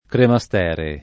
[ krema S t $ re ]